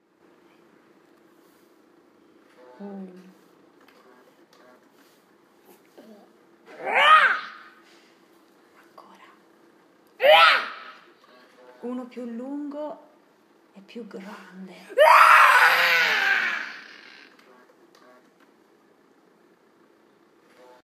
Vi consigliamo di alzare il volume perchè la registrazione è un po’ bassa e di avvicinare l’orecchio alla cassa o indossare cuffie insonorizzate per goderne appieno.
Bene…se sarete così gentili e intelligenti da seguire i nostri consigli, una volta ascoltato il flebile versetto rispondete a questa facile domanda (compilando il form qui sotto): DI CHE SPECIE DI DINOSAURO SI TRATTA?